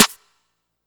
VB_SNR.wav